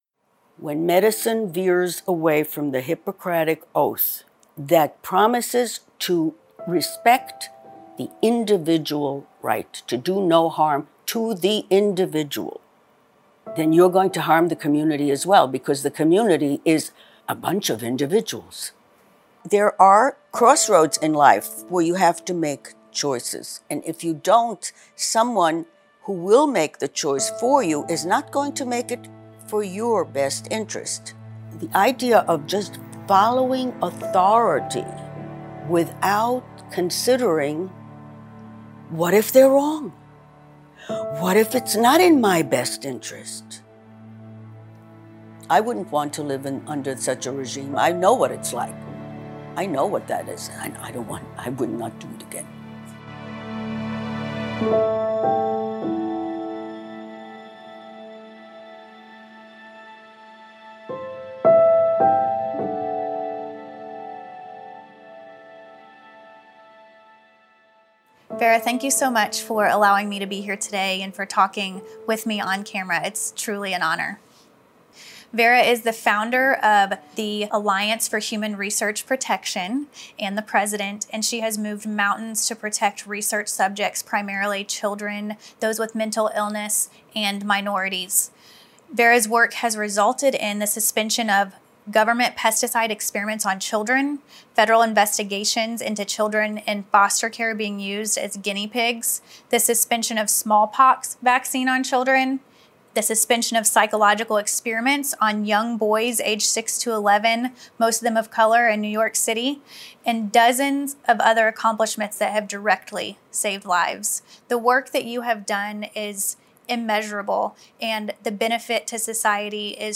Nazism, COVID-19 and the destruction of modern medicine: An interview